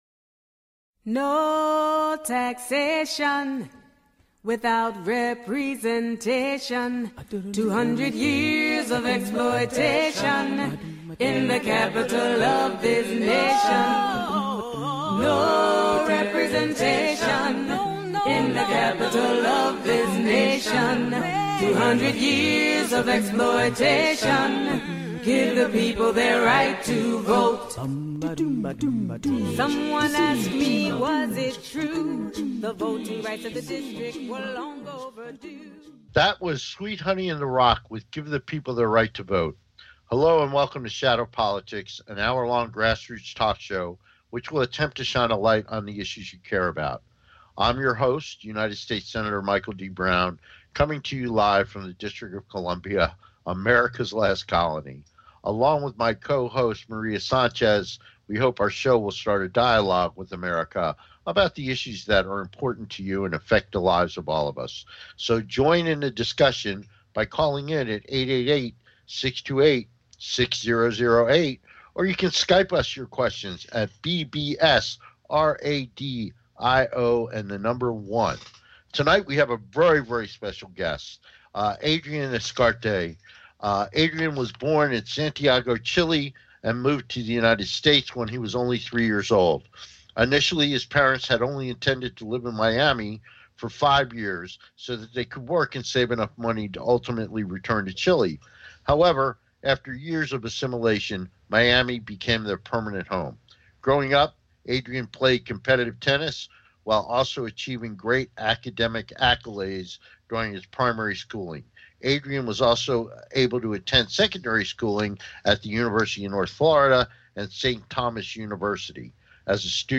Shadow Politics is a grass roots talk show giving a voice to the voiceless.